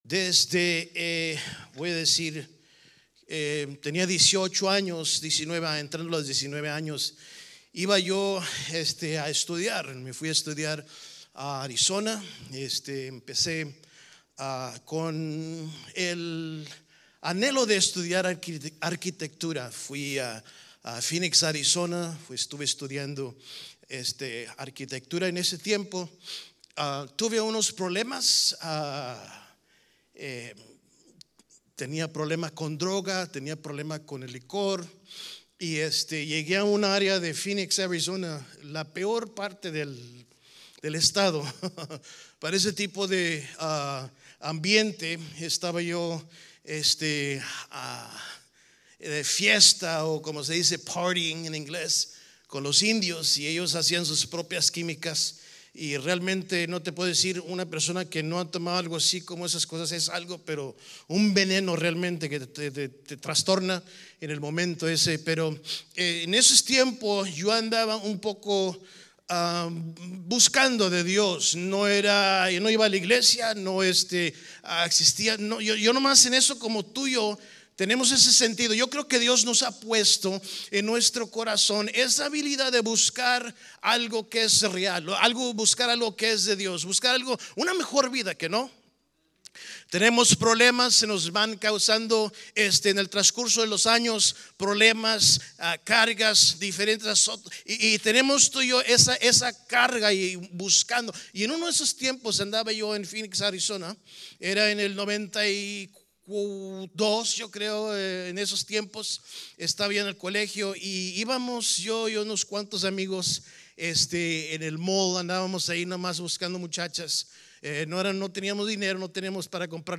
8-25-24-ESP-Sermon-Podcast.mp3